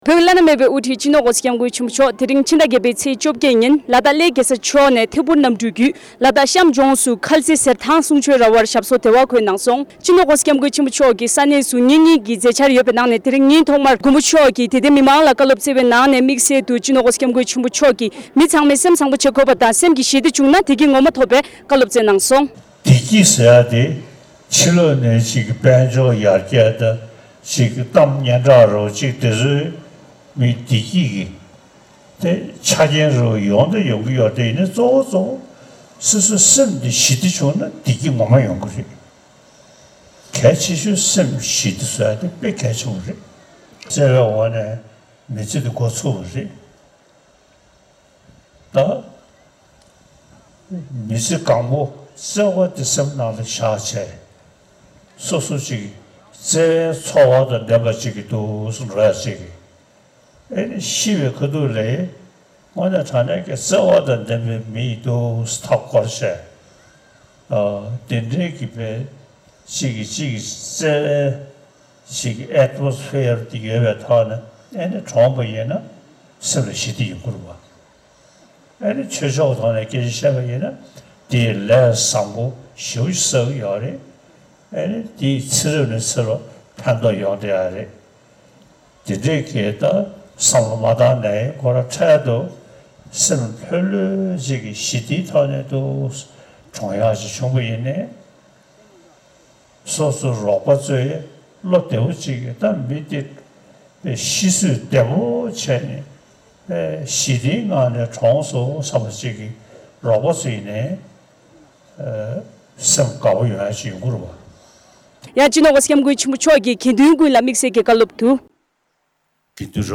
༸གོང་ས་མཆོག་གིས། སེམས་ལ་ཞི་བདེ་བྱུང་ན་བདེ་སྐྱིད་ངོ་མ་དེ་ཡོང། ཞེས་བཀའ་སྩལ་བ། ༸གོང་ས་མཆོག་ལ་དྭགས་གཤམ་མཁར་རྩེ་རུ་ཆིབས་སྒྱུར་བསྐྱངས་པའི་སྐབས། ༢༠༢༣།༠༨།༡༨ ཉིན།
སྒྲ་ལྡན་གསར་འགྱུར།